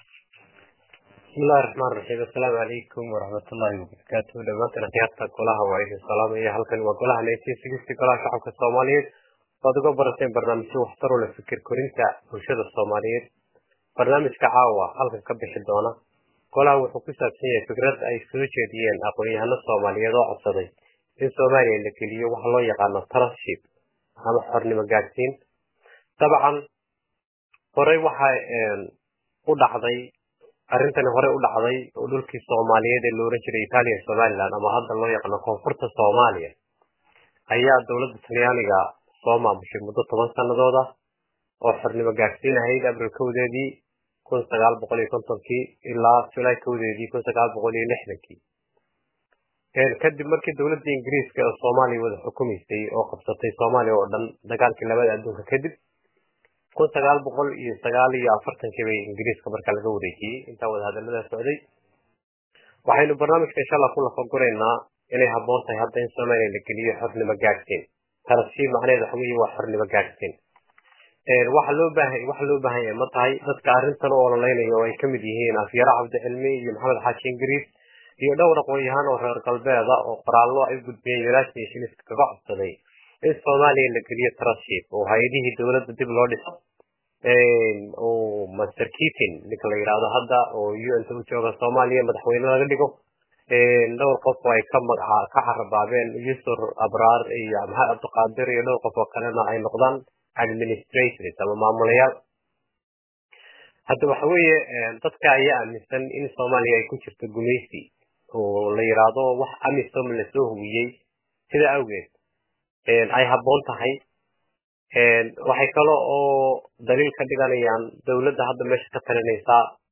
oo Waraysi la xiriira Soomaaliya & khataraha ku xeeran siiyey Golaha1960